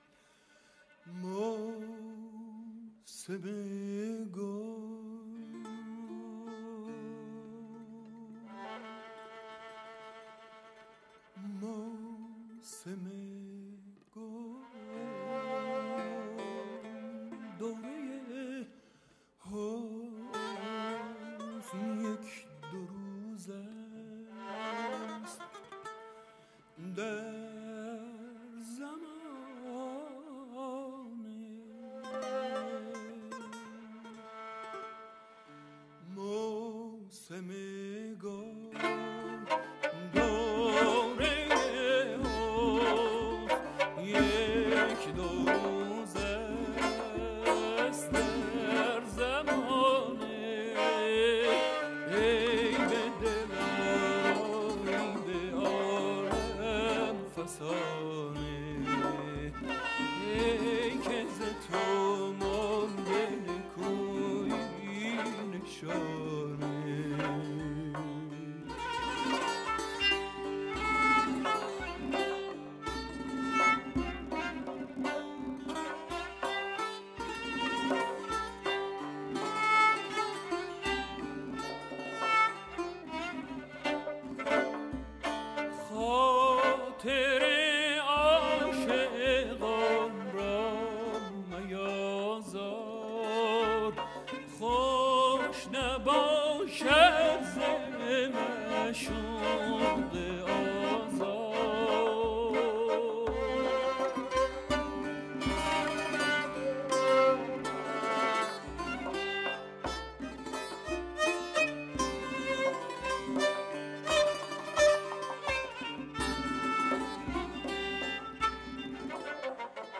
در کنسرت رادیو ملی ایتالیا
کمانچه
سه‌ تار
تمبک